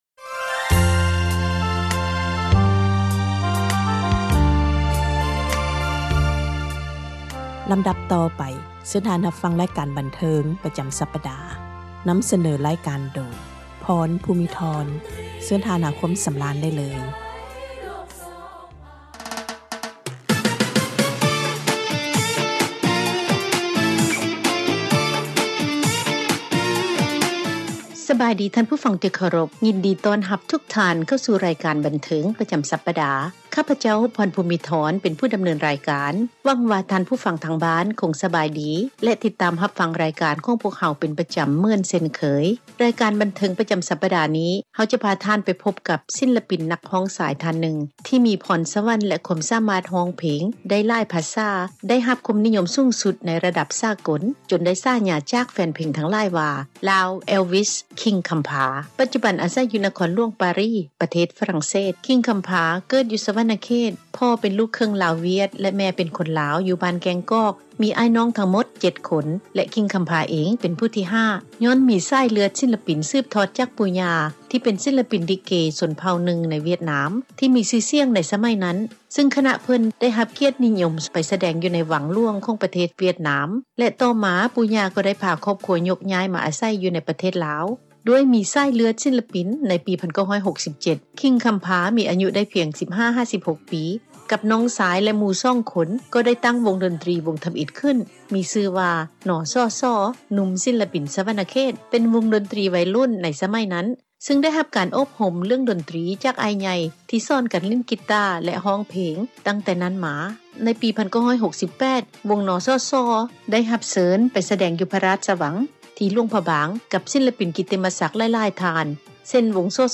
ສັມພາດ ສິລປິນ ນັກຮ້ອງ ນັກດົນຕຣີ ທີ່ສາມາດຮ້ອງເພງສາກົລ ໄດ້ຫຼາຍພາສາ